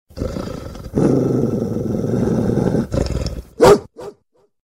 Категория: Звуки | Дата: 17.11.2012|